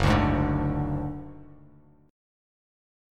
F#sus2#5 chord